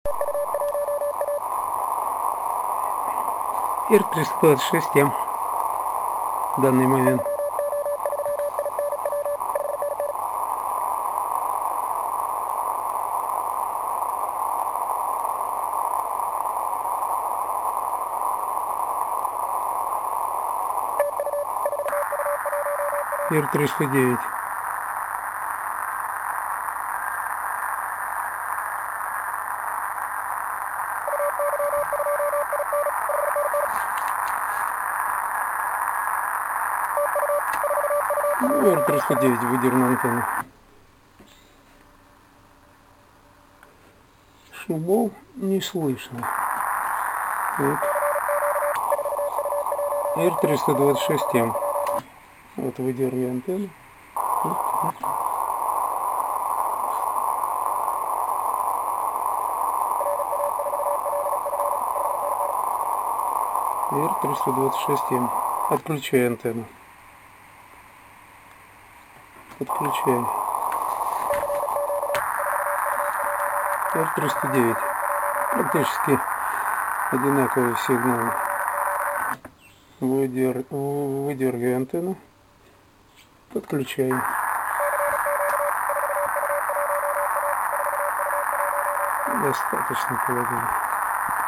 "Разминочное" QSO перед выходом.